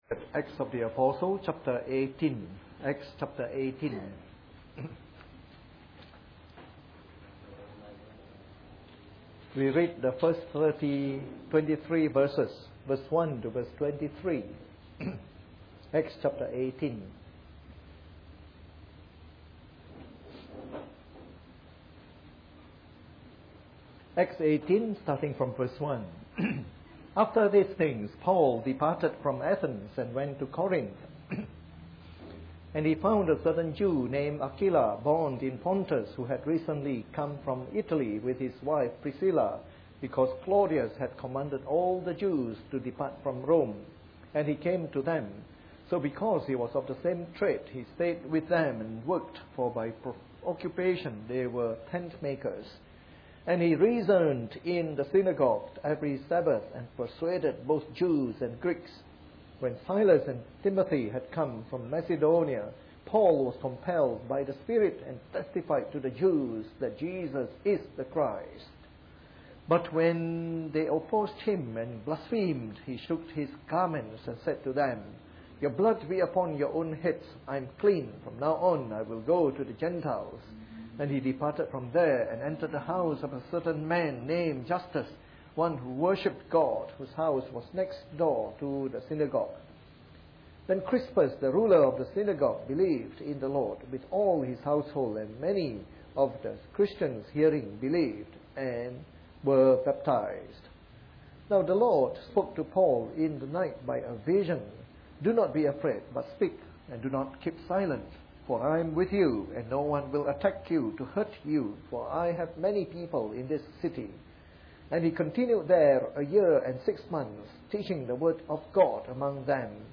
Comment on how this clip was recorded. Part of our series on “The Acts of the Apostles” delivered in the Evening Service.